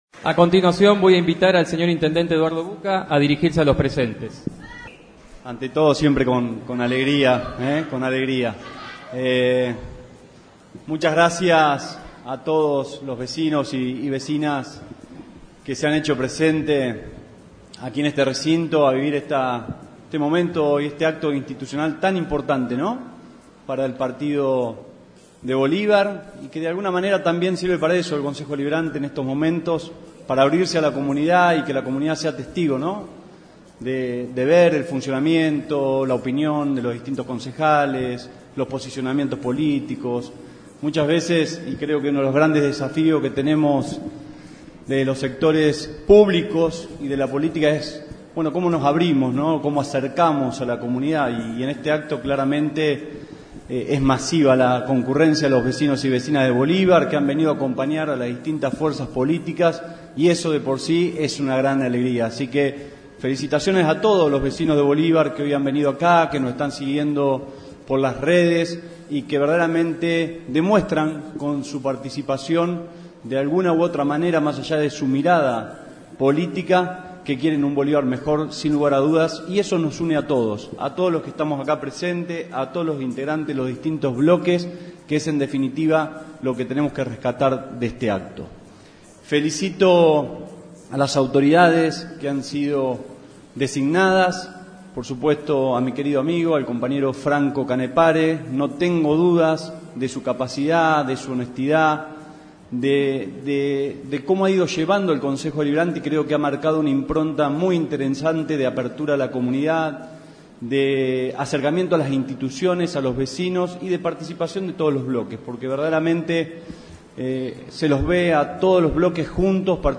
ASUNCION DEL INTENDENTE MUNICIPAL DR. EDUARDO BUCCA :: Radio Federal Bolívar
PALABRAS ALUSIVAS